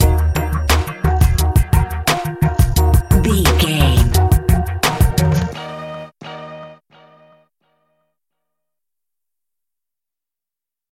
Uplifting
Aeolian/Minor
E♭
drum machine
synthesiser
percussion
hip hop
Funk
neo soul
acid jazz
confident
energetic
bouncy
funky